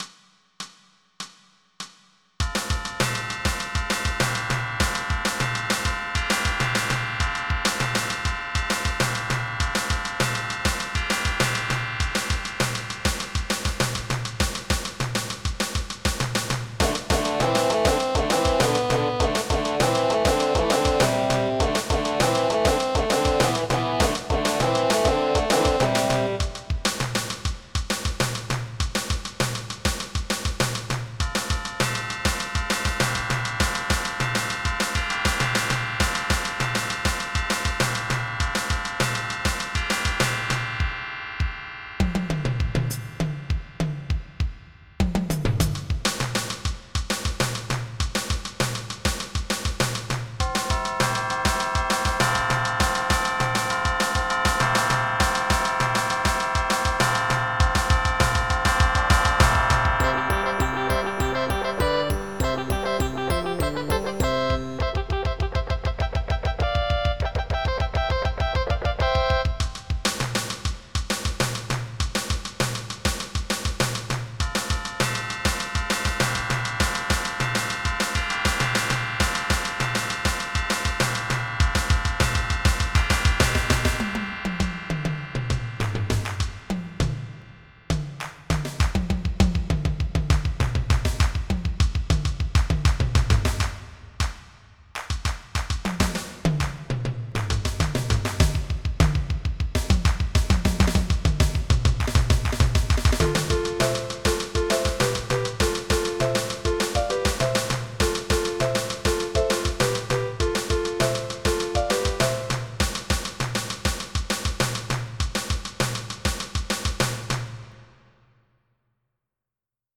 < prev next > MIDI Music File
2 seconds Type General MIDI